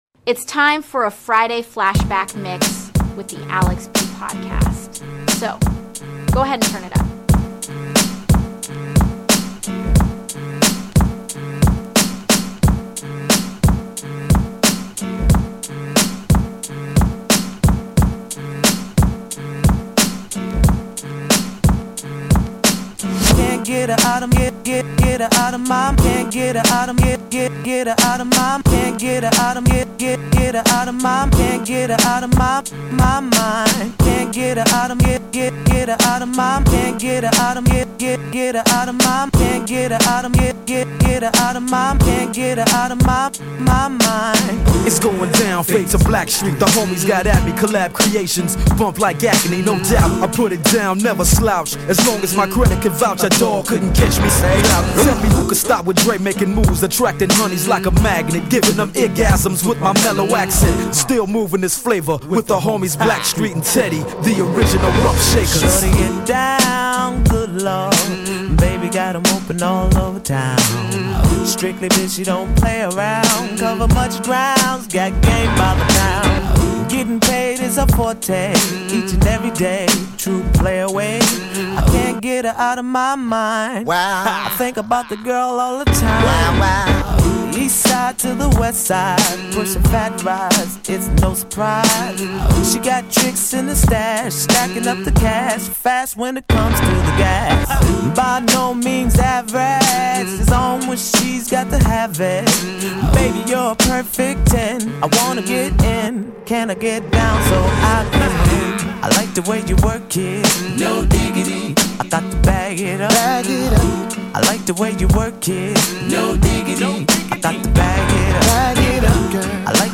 I talk, I play music, sometimes talk to other people.